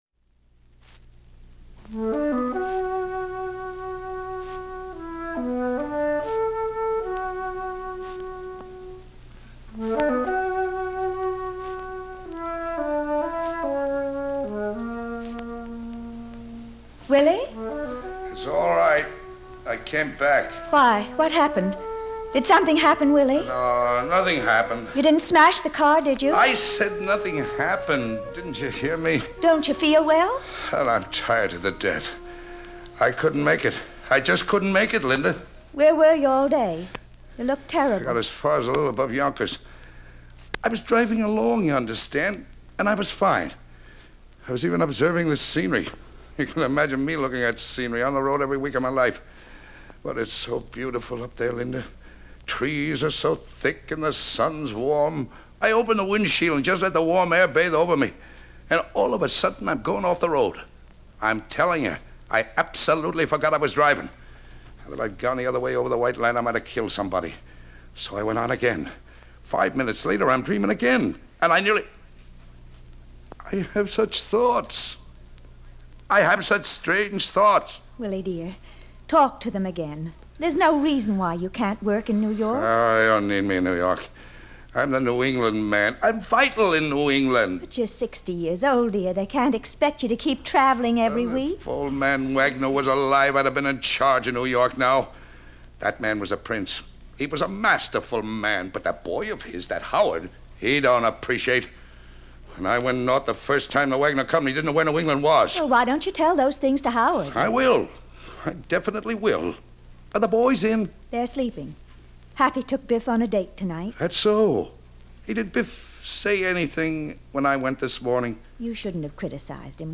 Audio kniha
Ukázka z knihy
This unusually powerful recording, made for radio in 1953, was directed by Elia Kazan who premiered the play. It features Thomas Mitchell and Arthur Kennedy as father and son. Willy, a travelling salesman, based in New York, relentlessly chases material success.